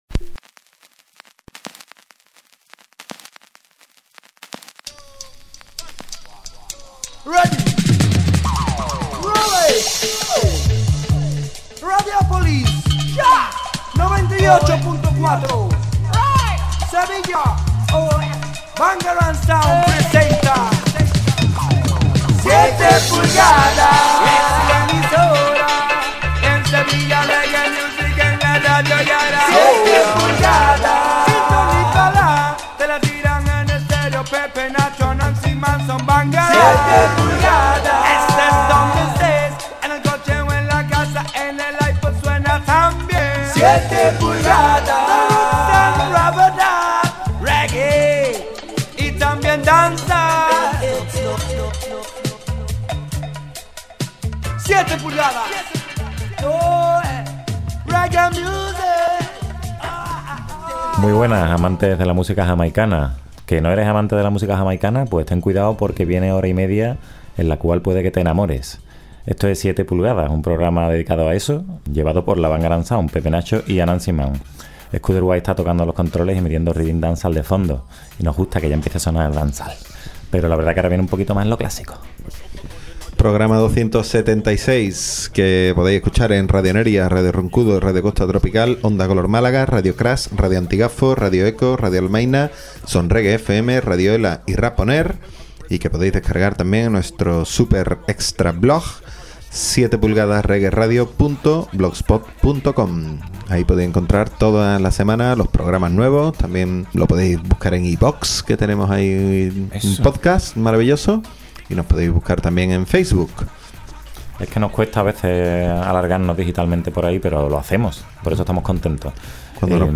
Presentado y dirigido por la Bangarang Sound y grabado en la Skuderbwoy House.